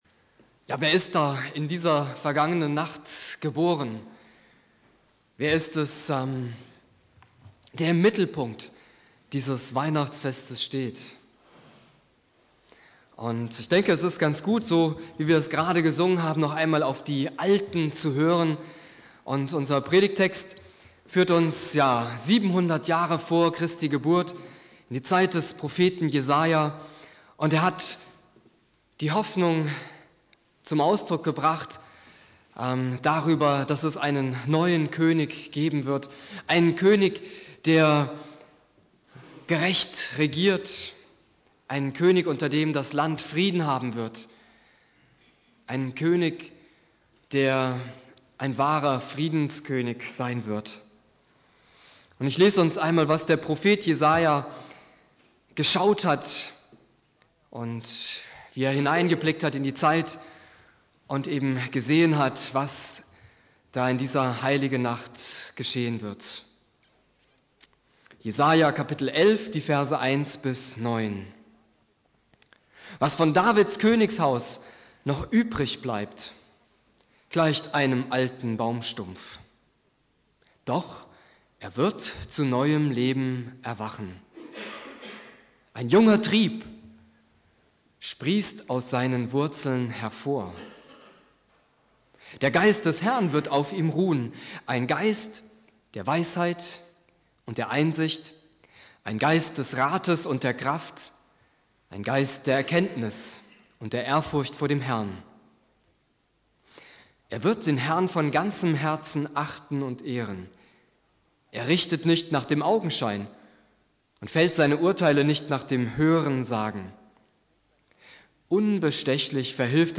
1.Weihnachtstag